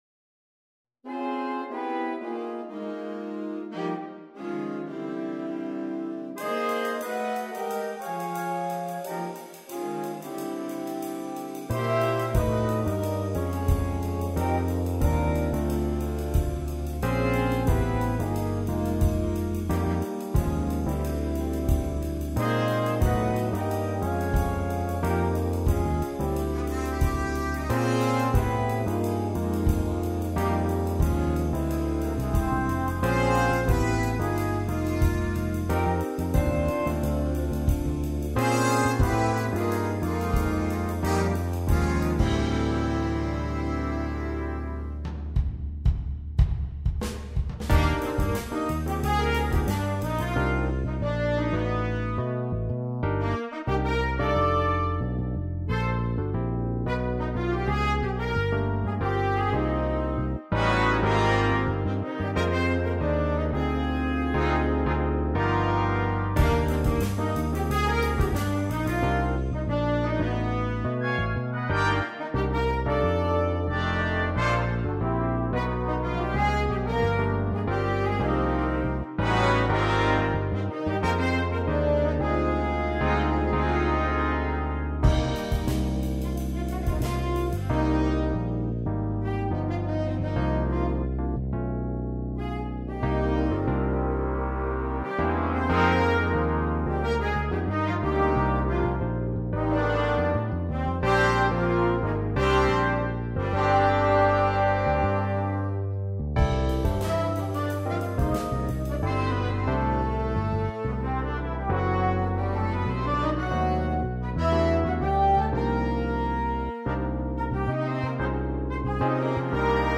Piano
Guitar
Bass
Drums